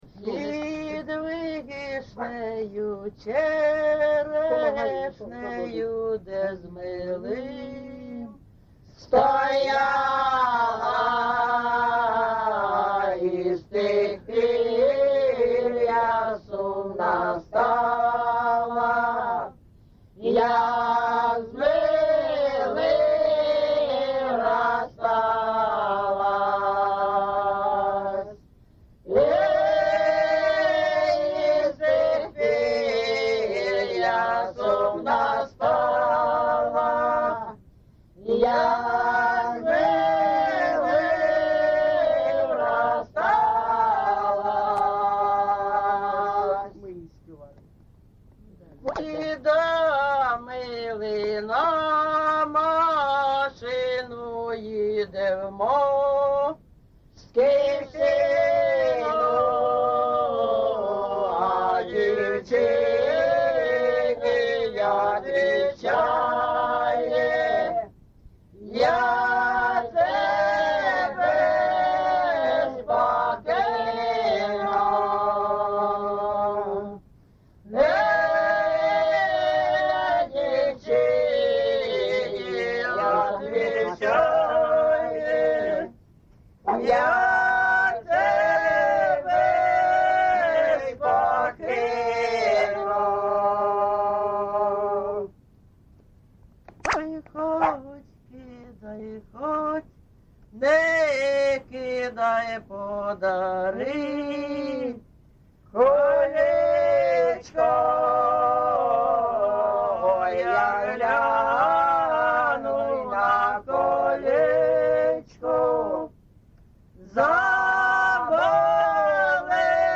ЖанрПісні з особистого та родинного життя
Місце записус. Андріївка, Великоновосілківський район, Донецька обл., Україна, Слобожанщина